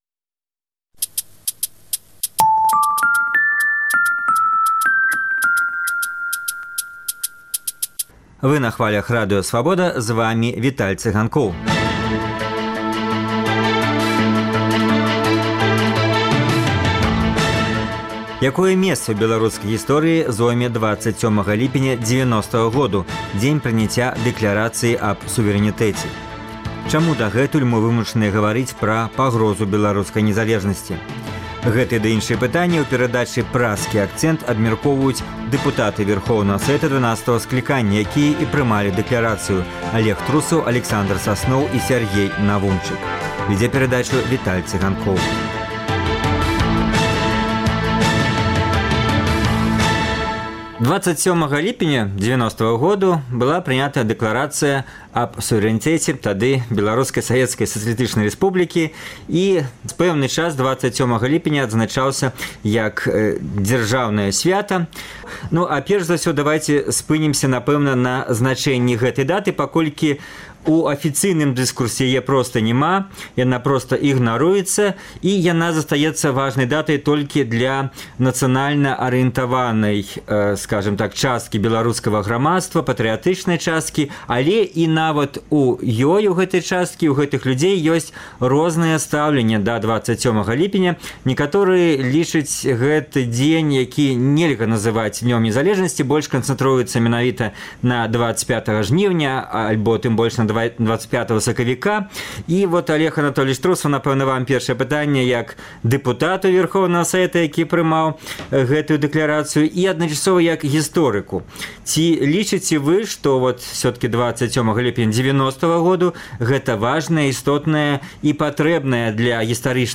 Чаму дагэтуль мы вымушаныя гаварыць пра пагрозы беларускай незалежнасьці? Гэтыя пытаньні ў Праскім акцэнце абмяркоўваюць дэпутаты Вярхоўнага Савета Беларусі 12 скліканьня, якія і прымалі Дэклярацыю аб сувэрэнітэтце, Алег Трусаў, Аляксандар Сасноў і Сяргей Навумчык.